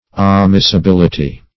Amissibility \A*mis`si*bil"i*ty\, [Cf. F. amissibilit['e].